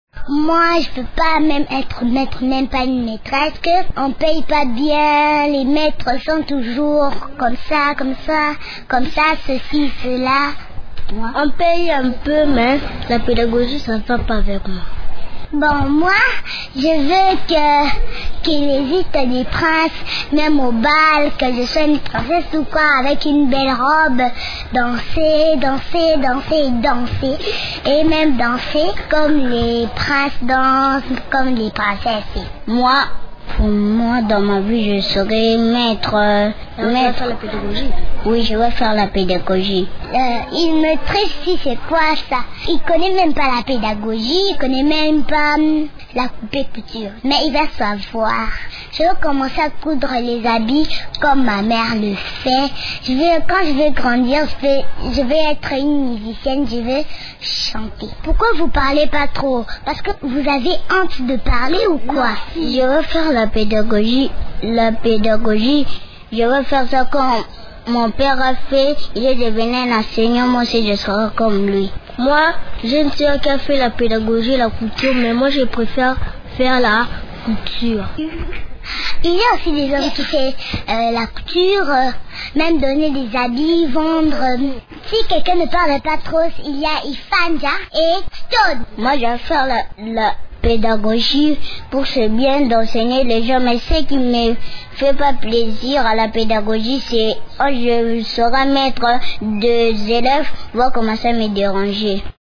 Les enfants répondent à la question : « Que ferez-vous quand vous serez grands ? ». Leurs projets d’avenir sont très différents les uns des autres.